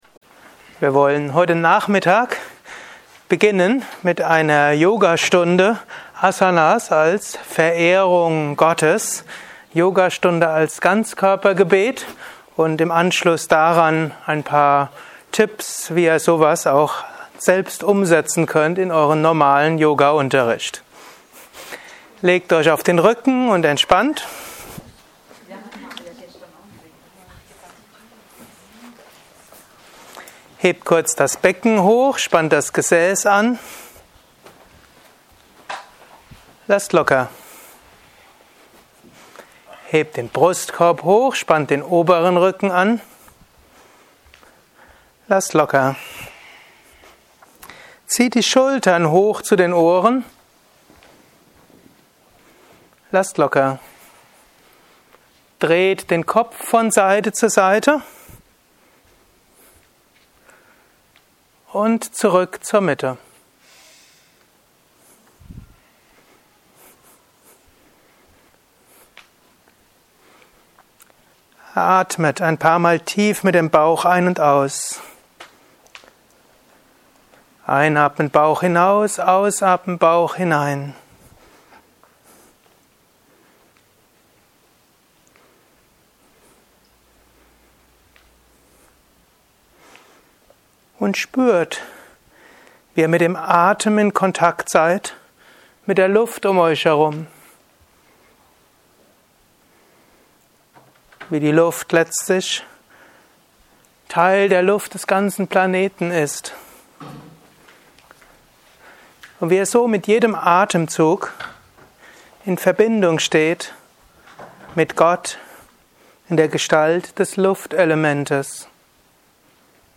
Spirituelle Yogastunde Mittelstufe 60 Minuten ~ Yoga Entspannung und Meditation Podcast
Bhakti_Yoga_Stunde_60_minuten_mit_Gebet.mp3